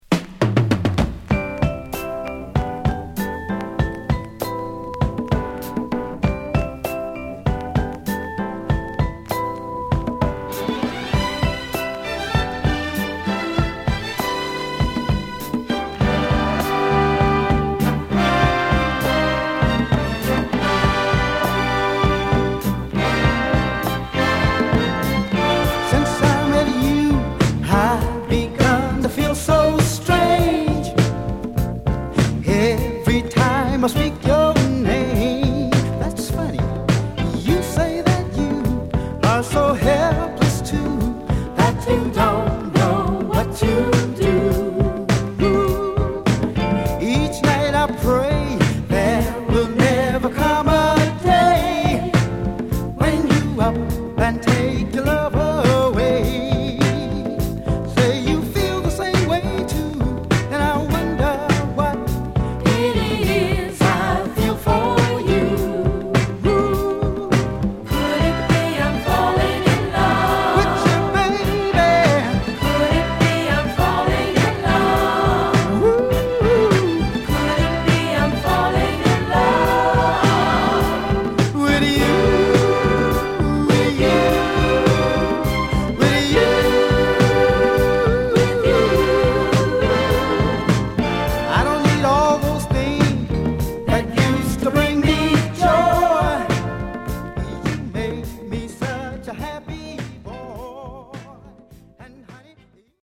デトロイト発のコーラスグループ